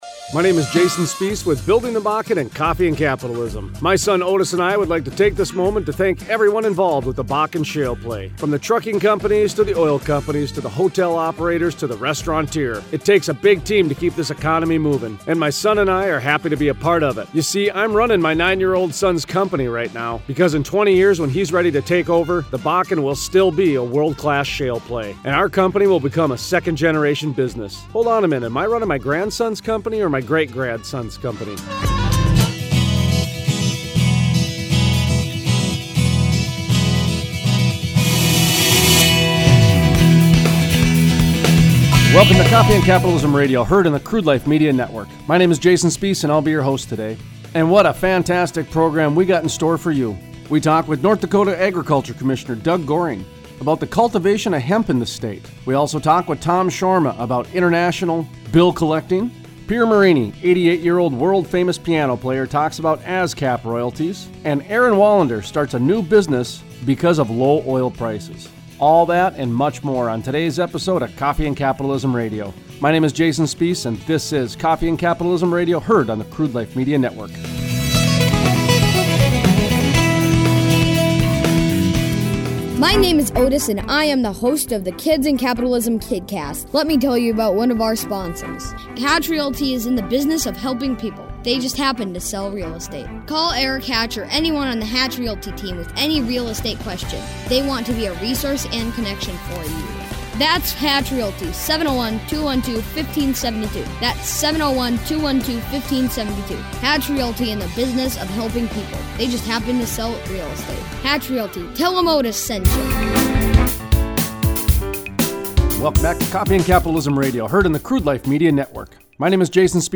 Emerging Communities Interview: Alan Walter, Ward County Commissioner Talks about the new airport in Minot and the amount of investment put into the terminal. He also talks about a couple other major projects in Berthold and Minot.